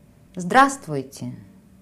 Just click on the languages below to hear how to pronounce “Hello”.